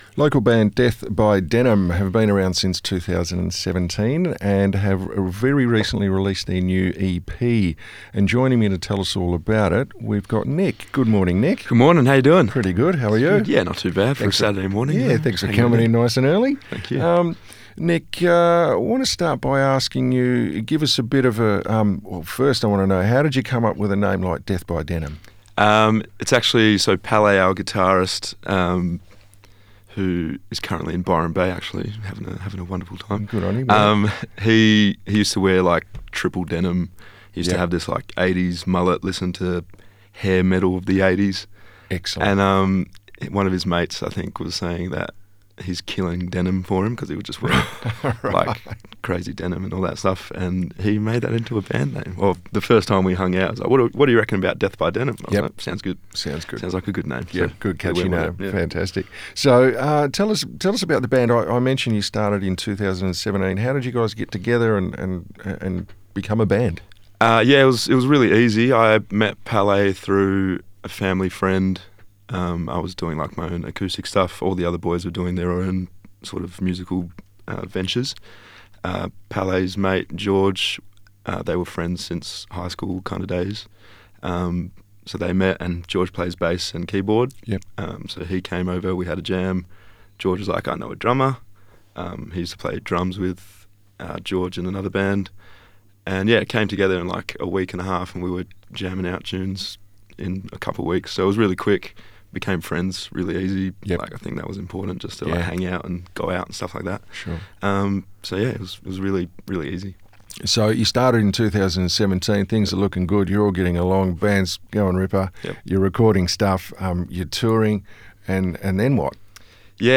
Death-By-Denim-Interview.mp3